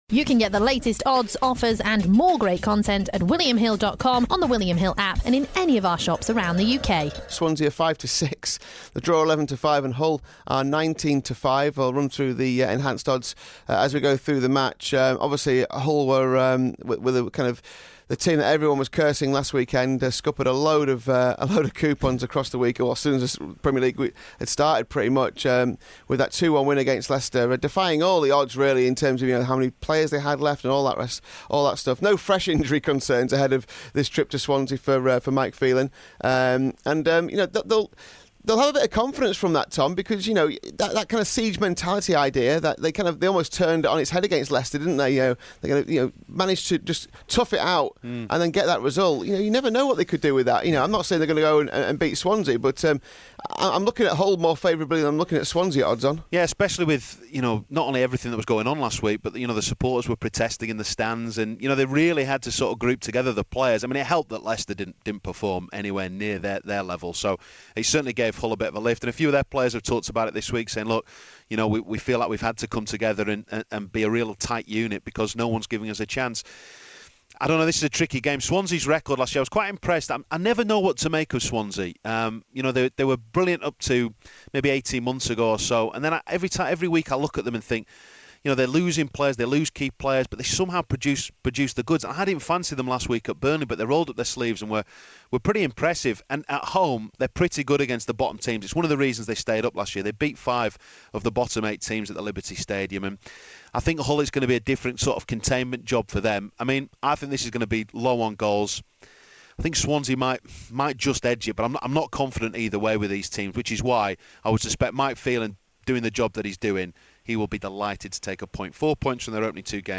Swansea v Hull. This is an excerpt from The Punt podcast.